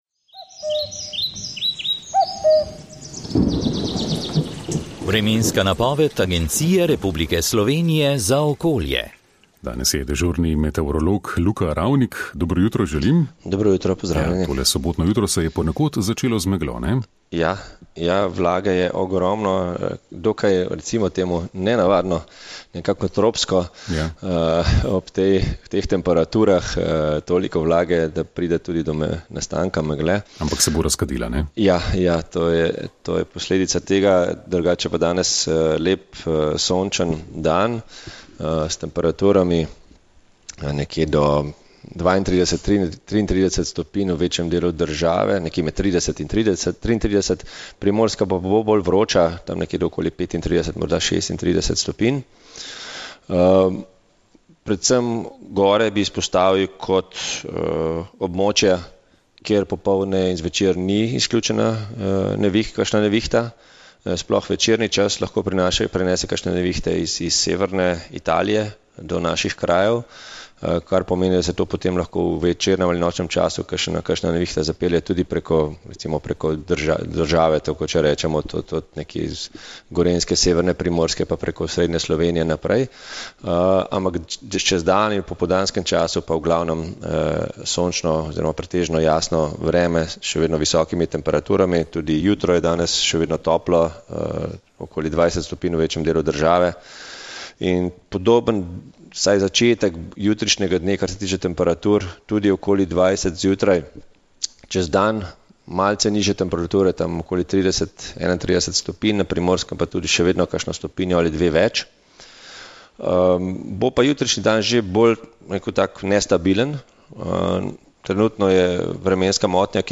Vremenska napoved 01. avgust 2020